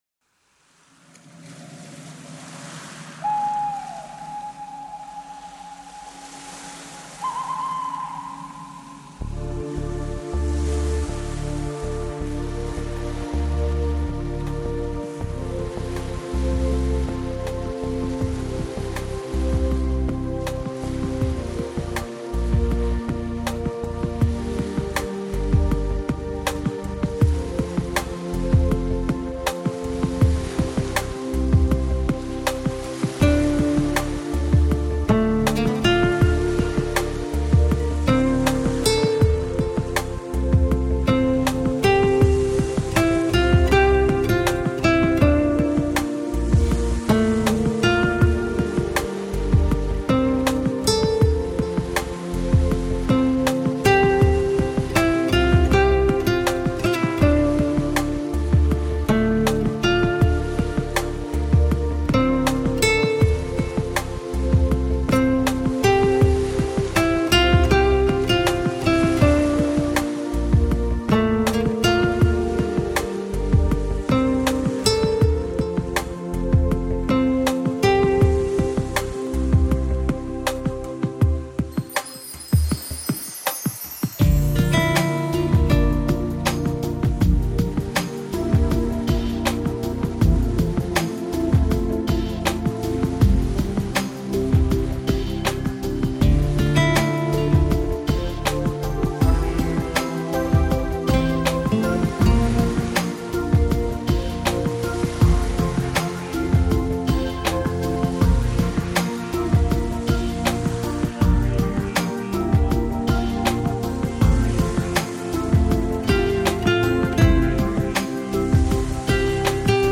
увы, но здесь не при делах:( шум волн слышно, каплю лишь добавлю.. музыку:)